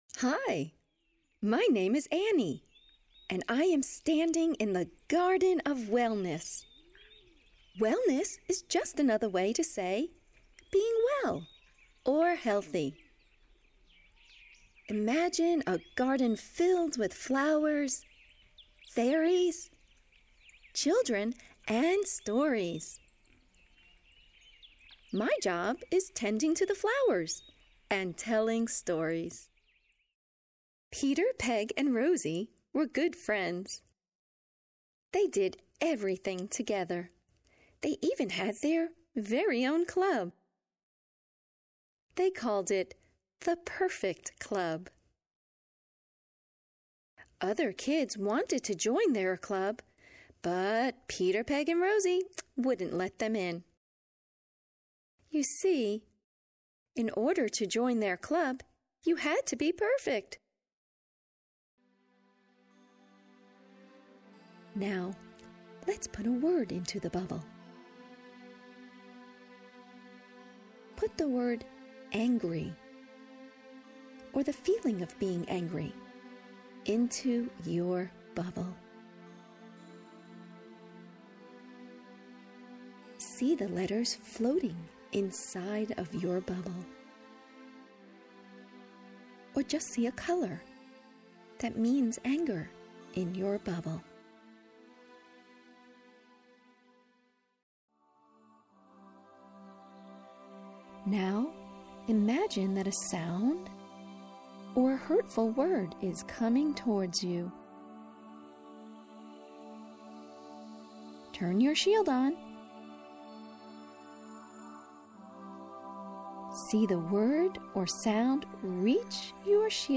Indigo Dreams: Garden of Wellness is a 60-minute audiobook designed to entertain your child while introducing them to research-based, relaxation, and stress management techniques.
Female narration is accompanied by calming sounds of nature.